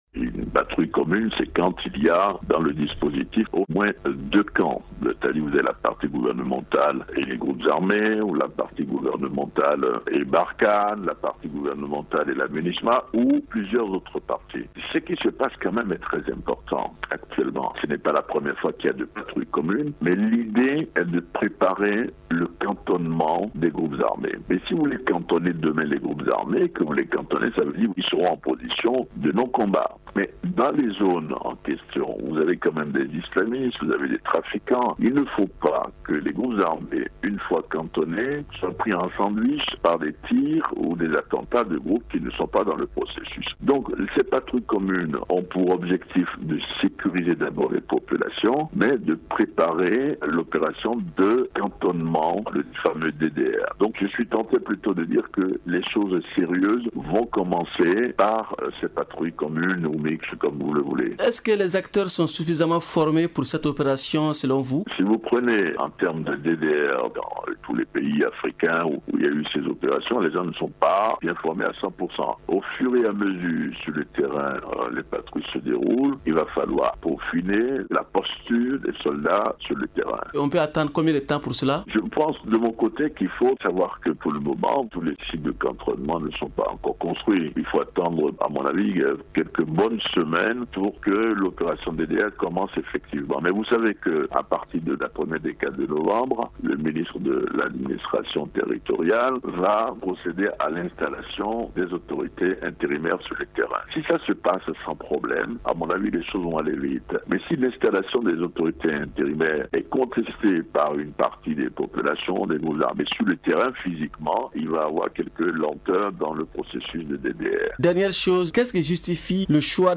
Il répond aux questions